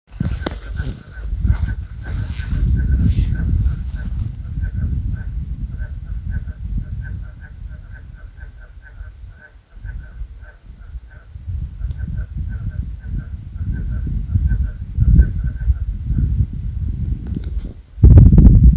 Laguna-Runtuyoc---Jujuy--012521.mp3
Clase: Amphibia
Orden: Anura
Certeza: Vocalización Grabada